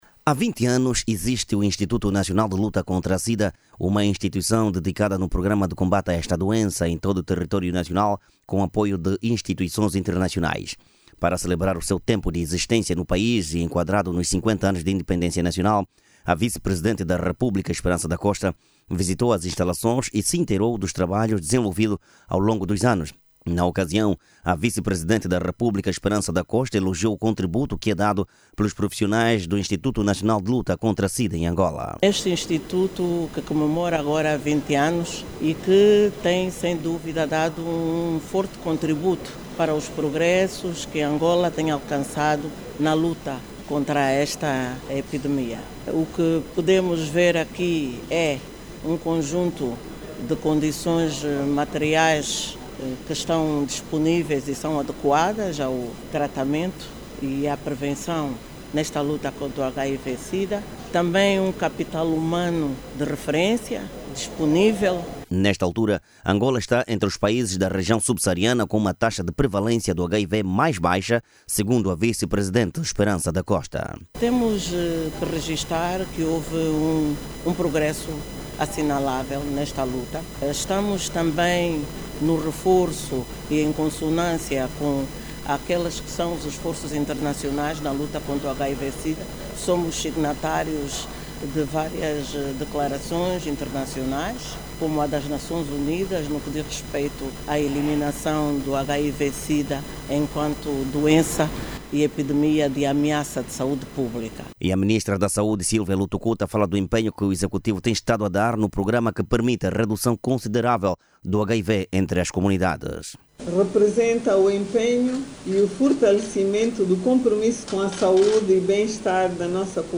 As declarações foram feitas nesta segunda-feira, no final da visita ao Instituto Nacional de Luta Contra a Sida.